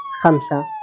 keyword-spotting
speech-commands